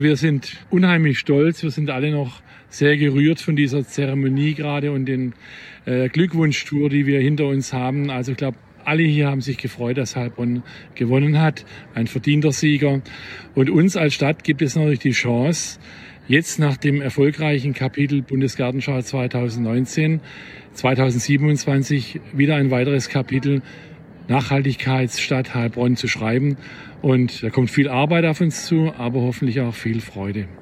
Bei seiner Rede bei der Preisverleihung hat Oberbürgermeister Harry Mergel der Europäischen Kommission für den Titel gedankt. Es sei eine große Ehre, aber auch eine große Verantwortung. Die Stadt zeigt, dass Industrie und Klimaschutz zusammen funktionieren können, so Mergel weiter.